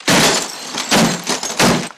Glass Smash
Glass Smash with Telephone x3